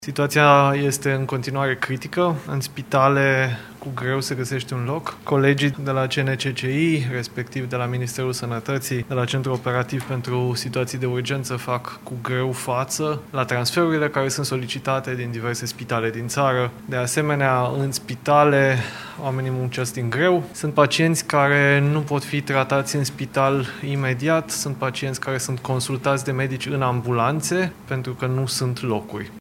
Ministrul Sănătății, Vlad Voiculescu cataloghează situația drept critică: